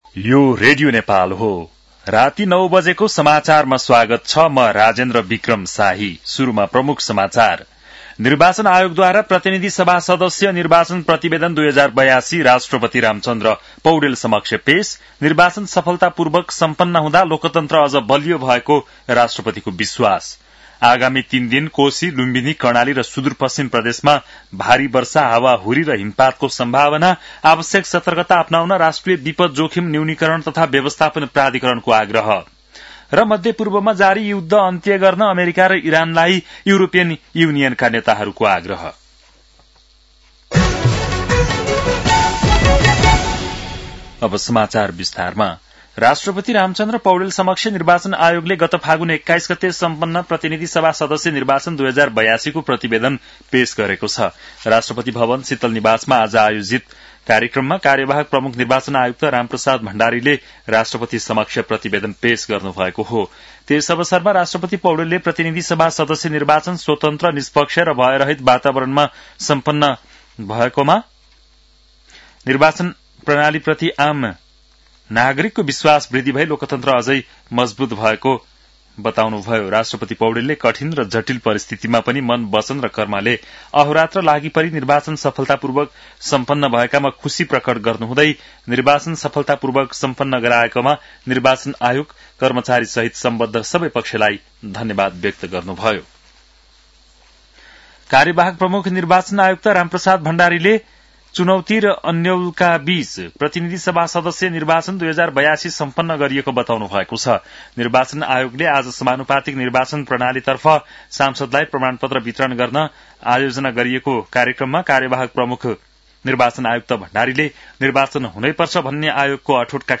बेलुकी ९ बजेको नेपाली समाचार : ५ चैत , २०८२
9-pm-nepali-news-12-05.mp3